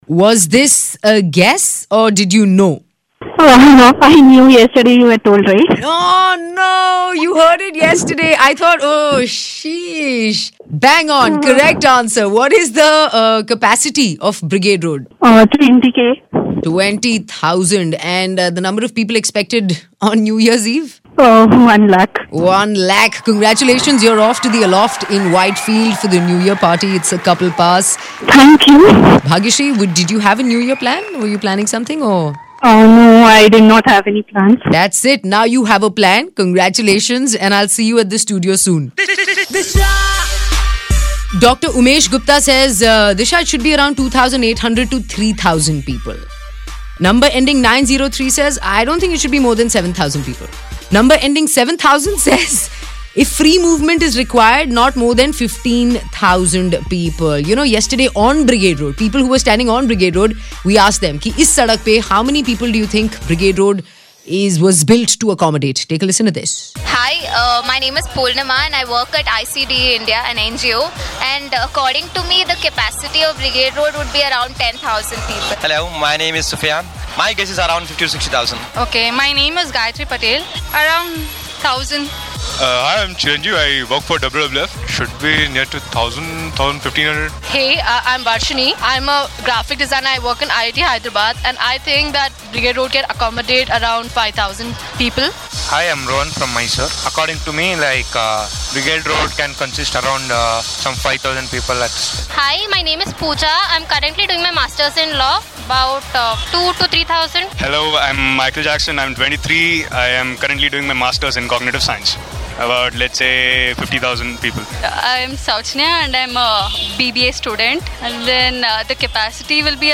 We went out and asked the people about the size of brigade road, and about how many people can it accomodate.